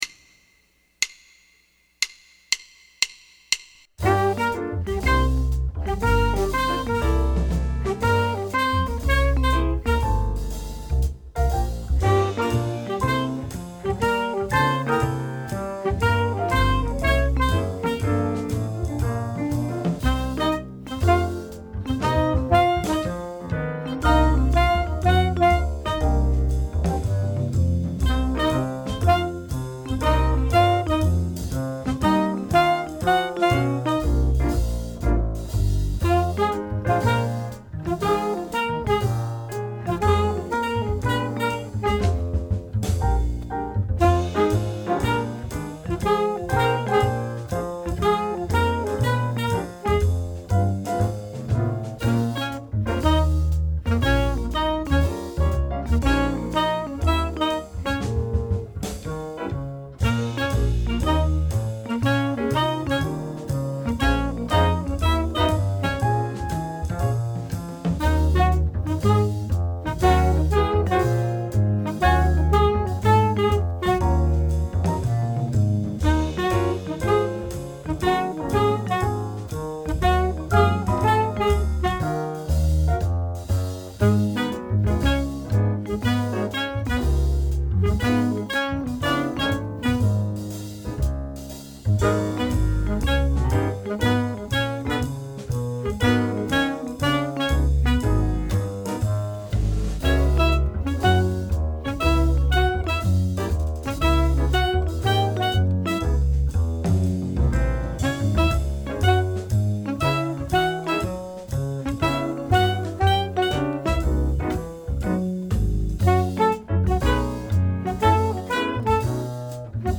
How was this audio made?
Concert key instruments start on the third line (bar 9), and take the d.c. Eb instruments begin at the last line (bar 45), and take the d.c. The clarinet is on the left, the alto saxophone on the right.